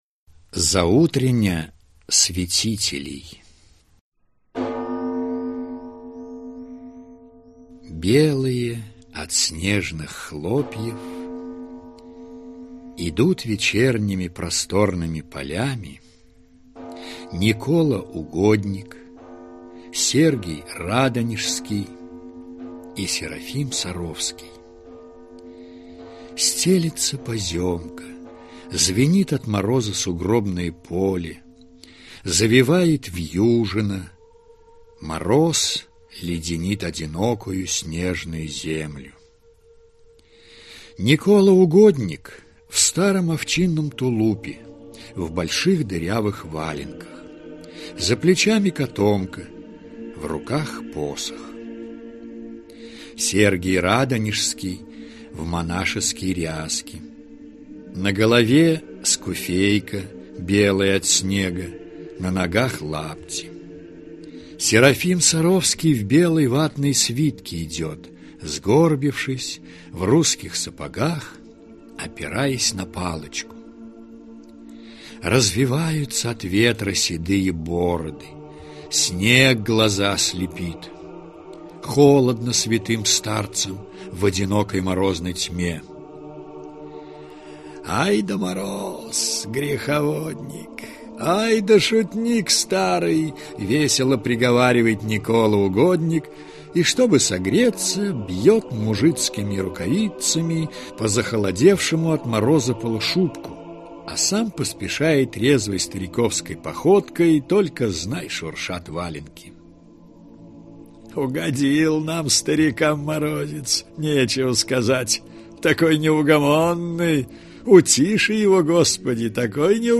Аудиокнига Заутреня святителей | Библиотека аудиокниг
Прослушать и бесплатно скачать фрагмент аудиокниги